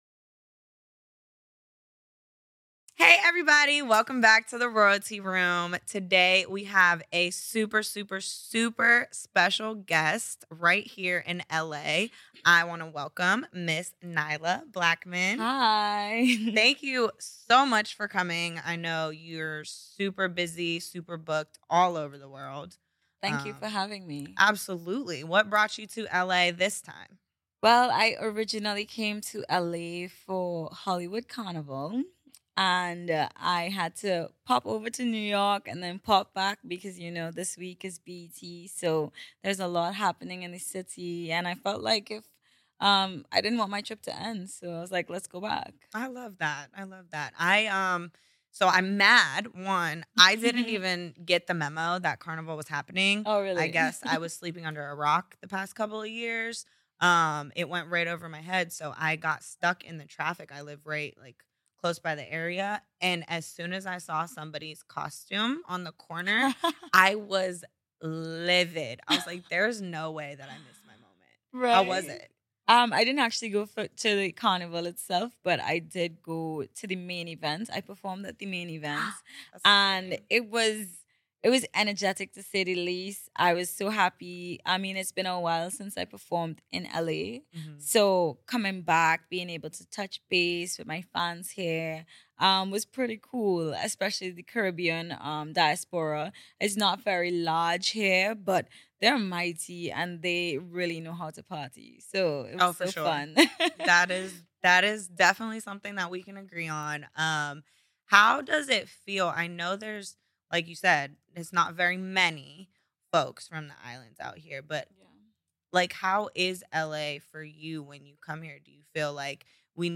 In the newest episode of The Royalty Room Podcast, we're thrilled to welcome the incredibly talented Nailah Blackman, a rising star in the Caribbean music scene, and SOCA ROYALTY. Tune in as she joins us to share her experiences performing at Hollywood Carnival, plans for the BET Awards, and a glimpse into her creative journey.